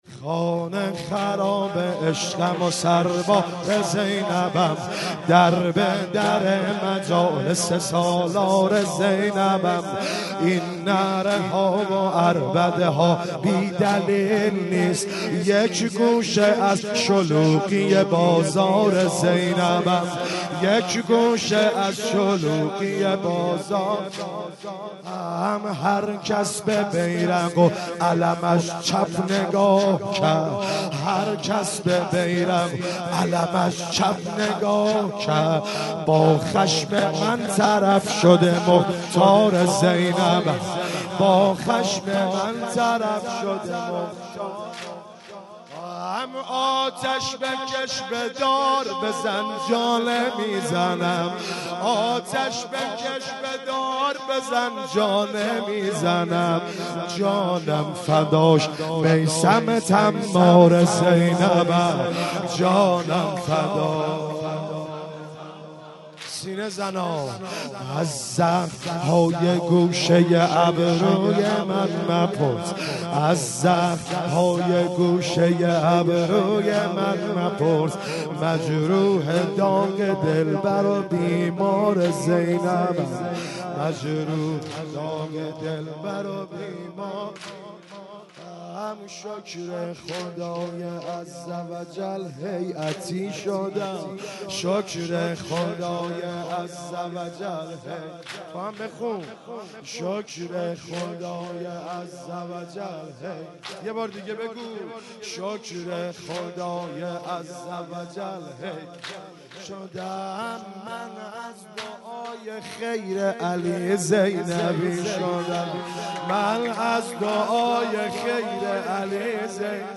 10 واحد خوانی حضرت زینب